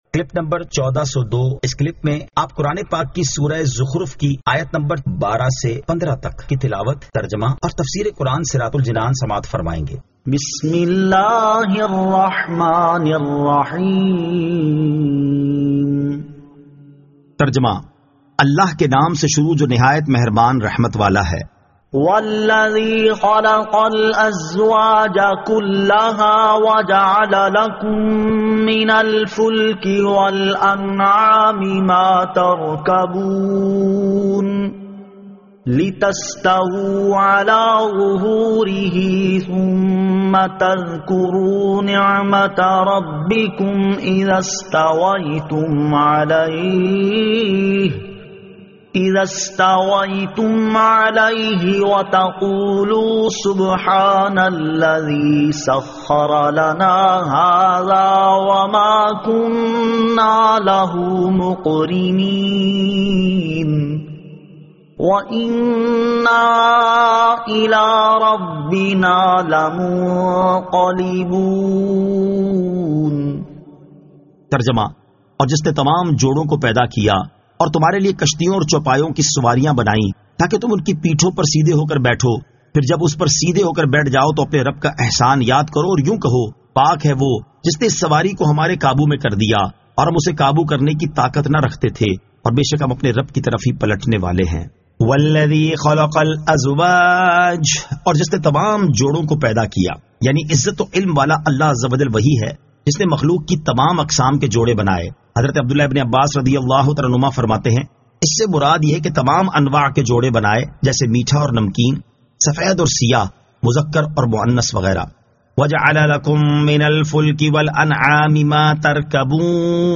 Surah Az-Zukhruf 12 To 15 Tilawat , Tarjama , Tafseer